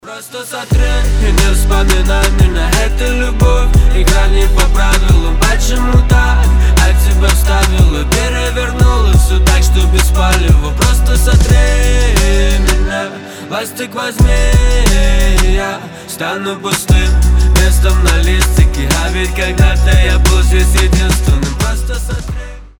мужской голос
грустные